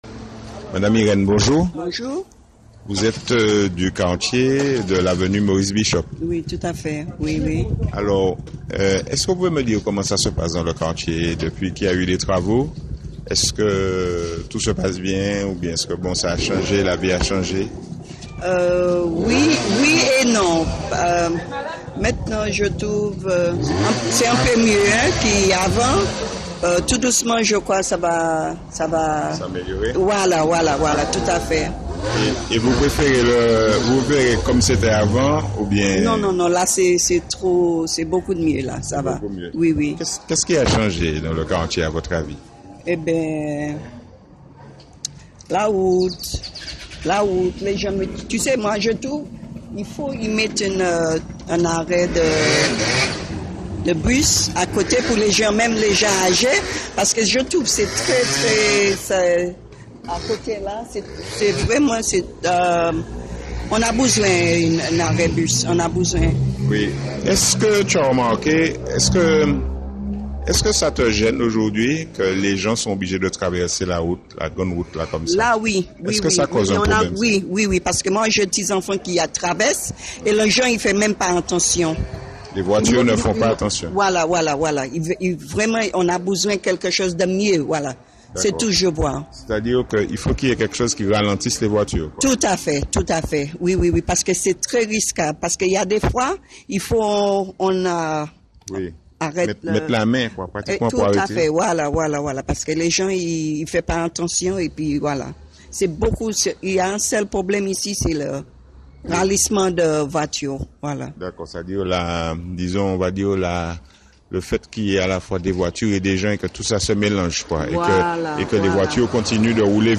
Sous la première photo il y a une rencontre avec un habitant.